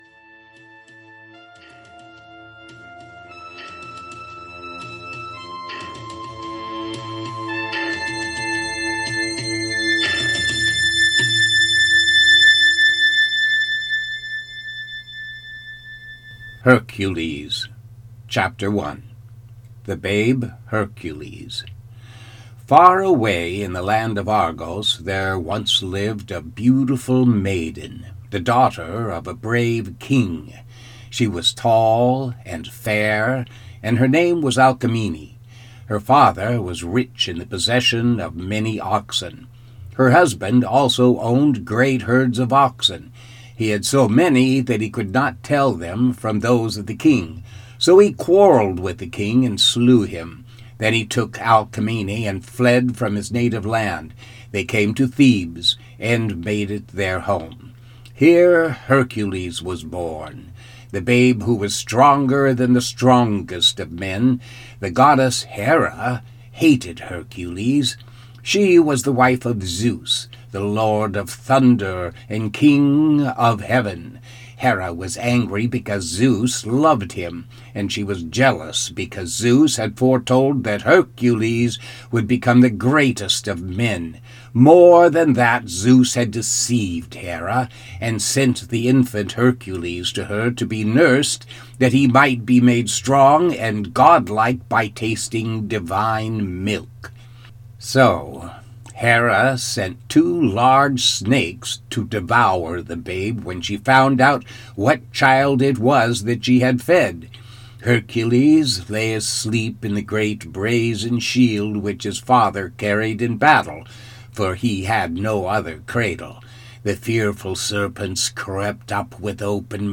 An AudioBook The demigod Hercules endures 12 labors to find his destiny.